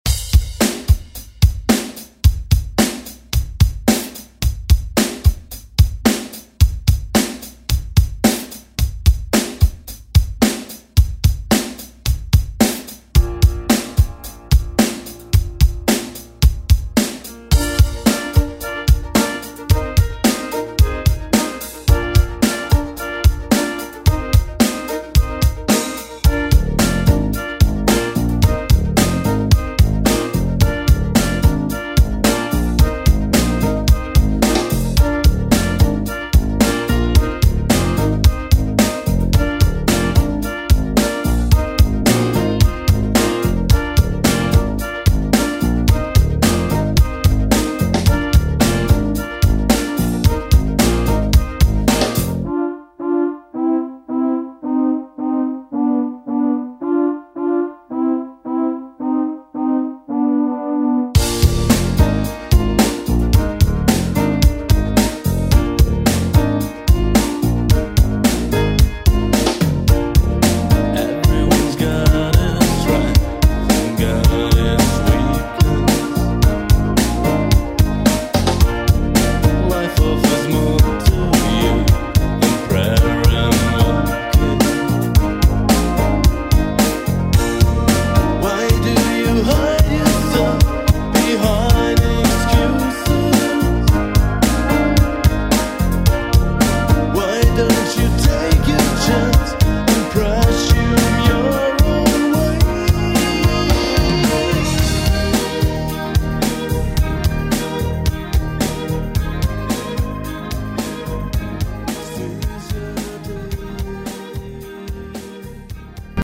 BPM: 110 Time